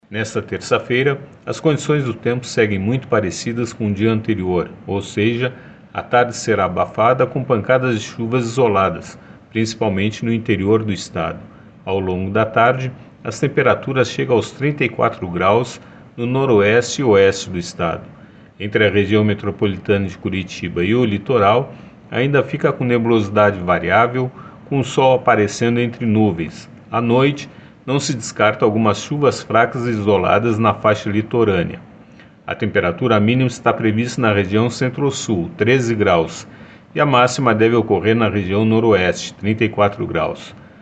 Nesta terça-feira, a expectativa é que o período da tarde será abafado, com pancadas de chuvas isoladas, principalmente em algumas áreas do interior do Paraná. Ouça o que diz o meteorologista do Simepar